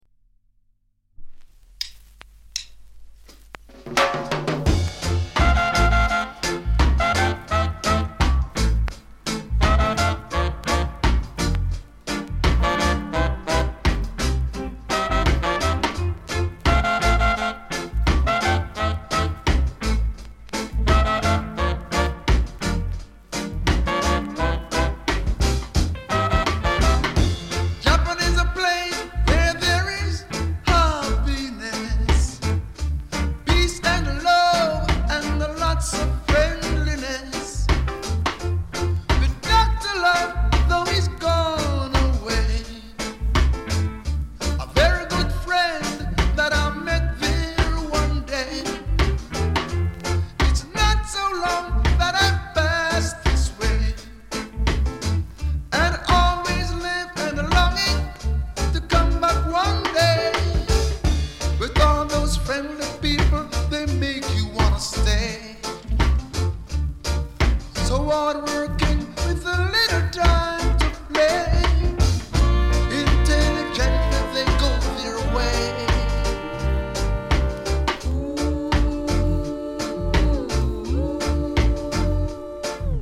category Reggae